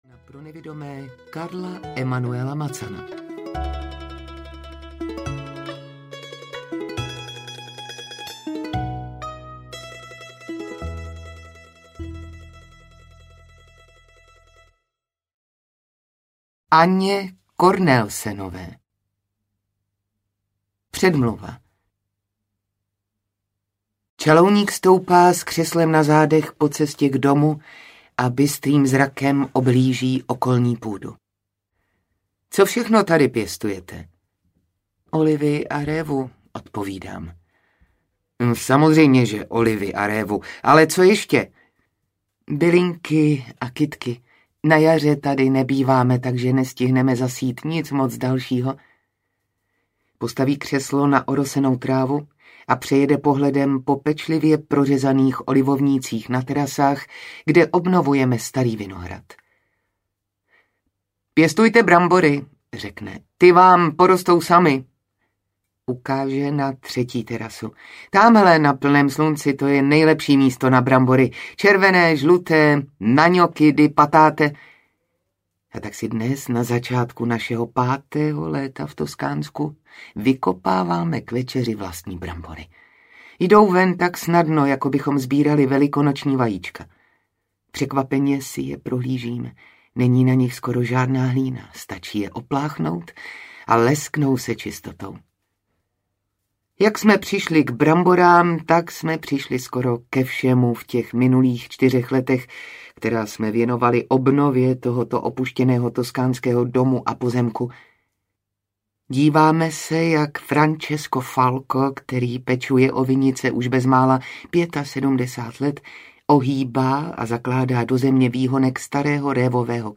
Pod toskánským sluncem audiokniha
Ukázka z knihy